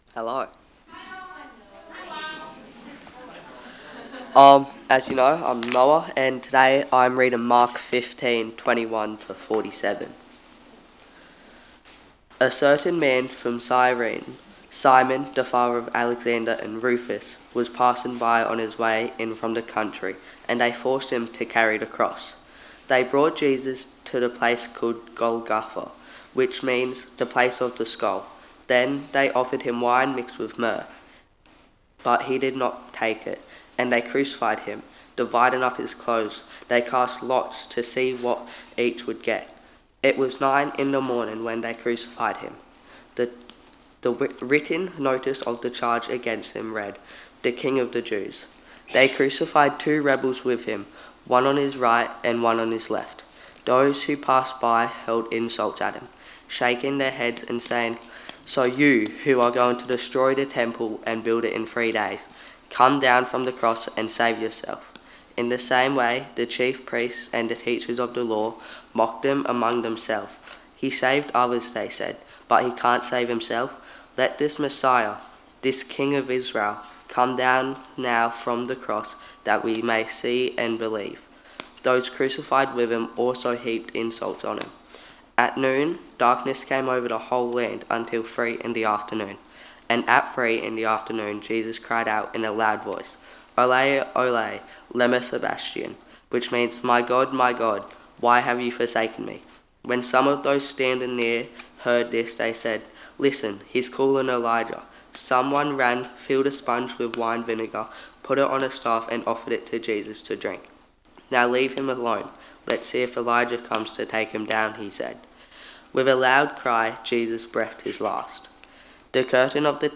Text: Mark 15: 21-47 Sermon